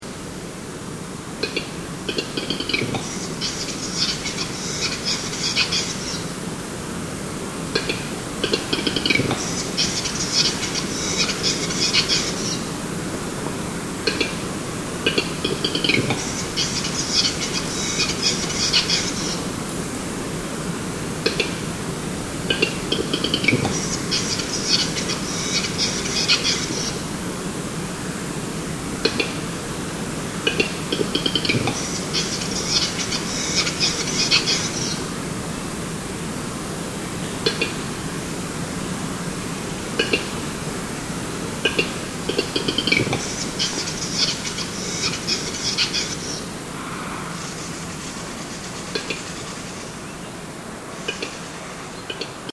Звуки глухаря
Самец издает этот звук